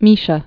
(mēshə, -shē-ə)